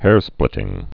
(hârsplĭtĭng)